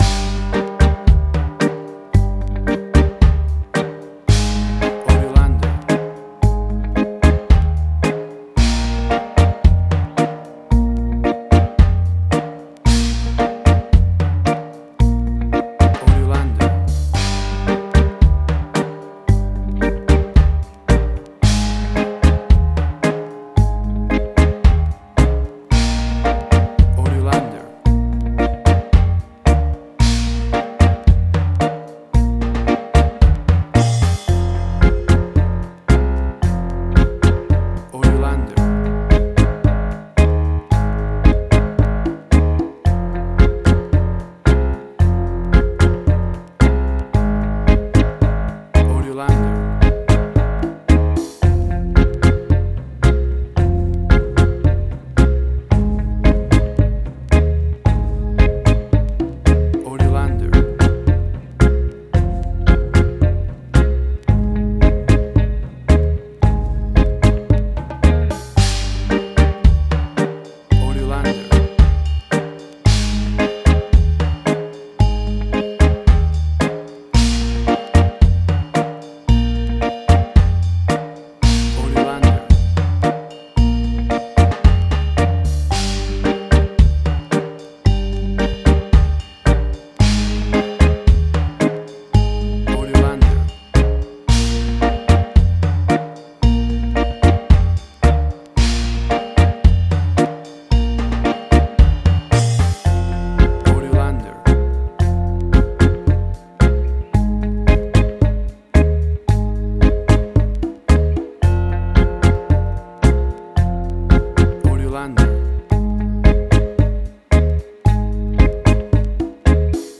Reggae caribbean Dub Roots
Tempo (BPM): 56